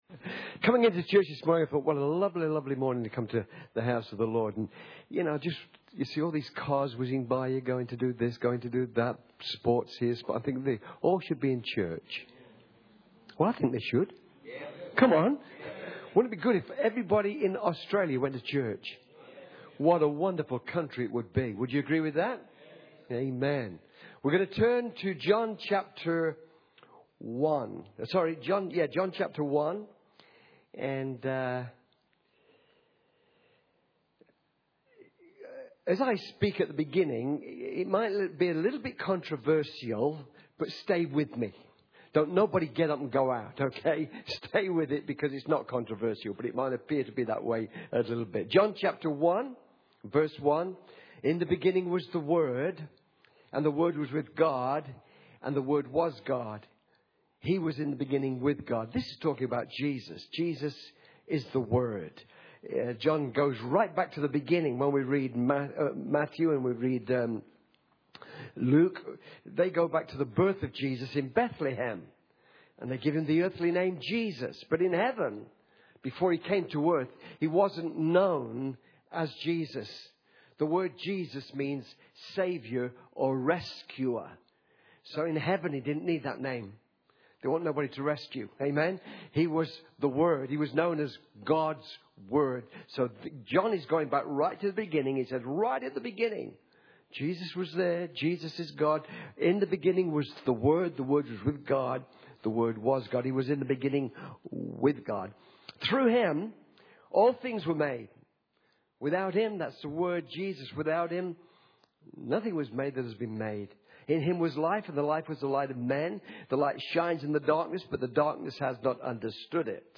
Guest Speaker Service Type: Sunday Morning « SOM p14